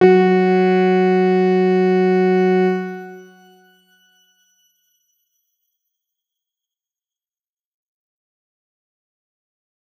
X_Grain-F#3-pp.wav